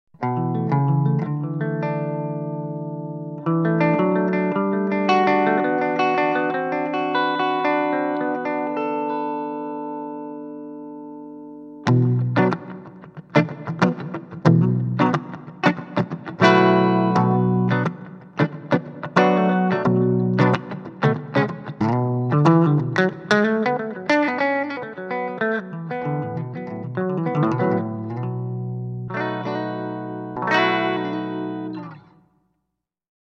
Les Paul Clean 2   :33